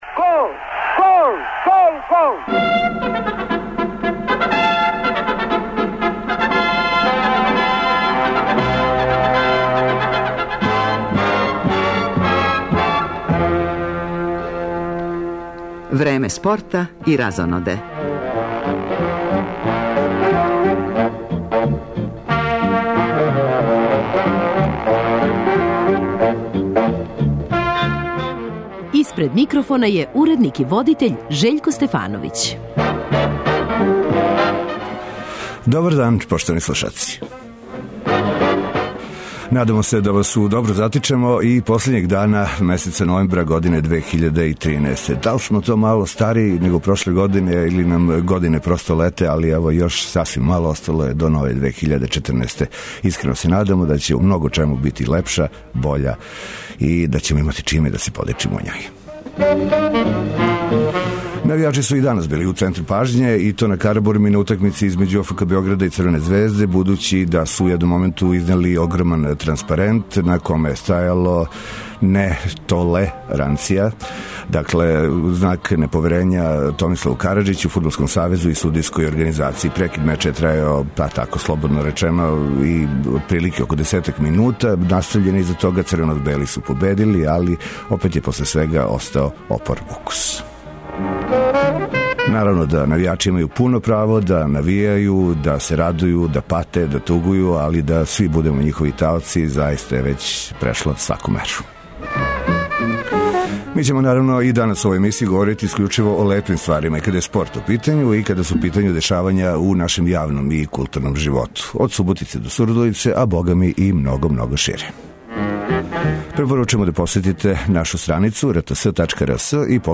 У спортском делу емисије најављујемо ватерполо спектакл који се игра у Бечеју - у Супер купу Европе састају се играчи Црвене звезде и крагујевачког Радничког. Од 18 часова наши репортери јављаће се са пријатељске утакмице рукометашица Србије и Хрватске, која се игра на последњем припремном турниру у Врњачкој бањи, уочи старта Светског првенства у нашој земљи.